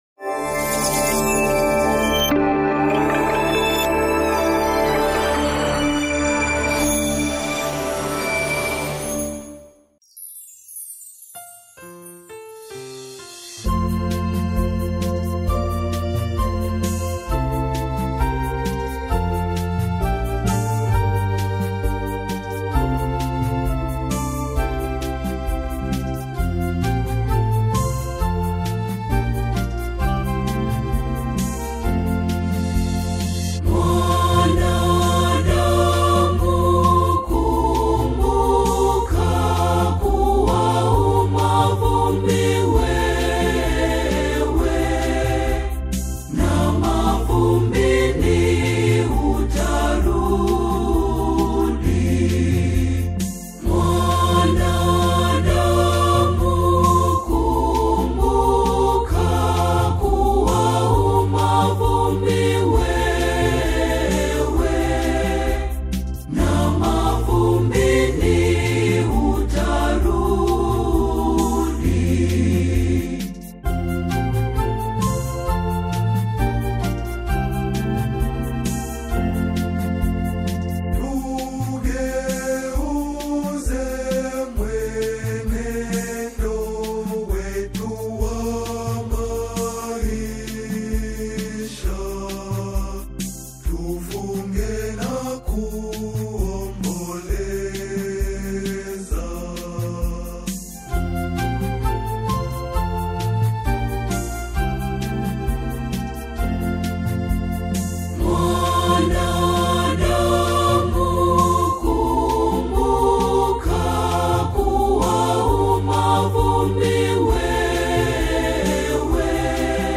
The hauntingly beautiful and harmonically rich single
is a "Forest & Stream" masterpiece of choral reflection.
organ